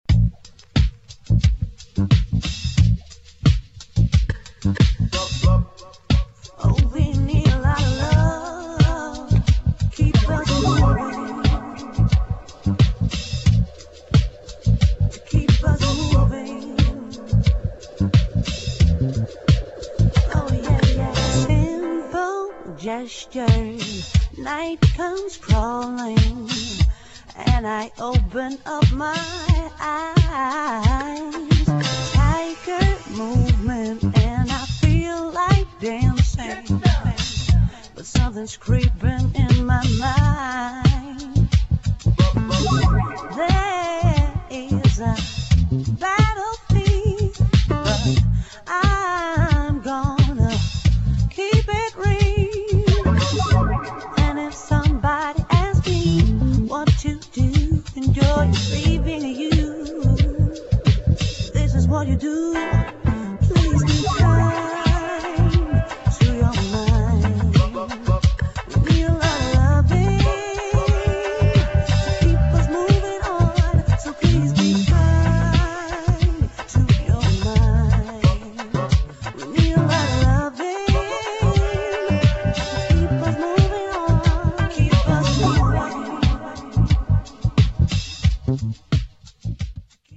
[SOUL / FUNK / JAZZ ]